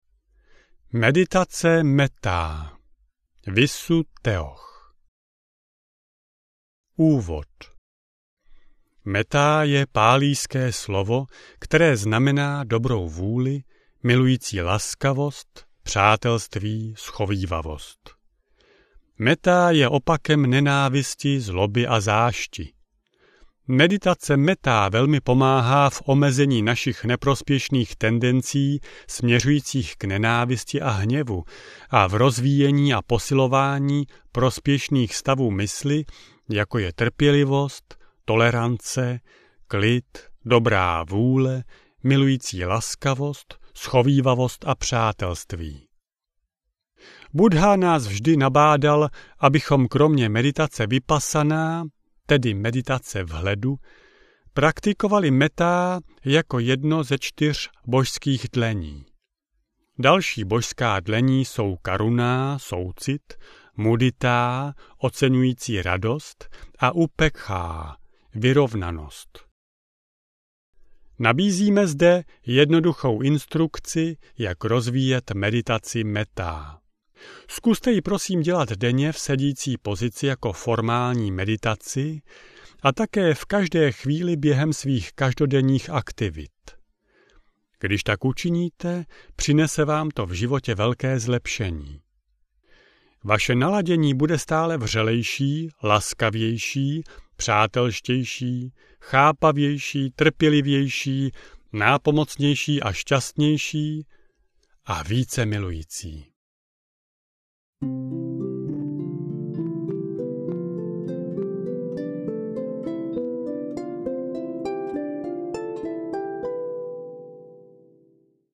Meditace mettá audiokniha
Ukázka z knihy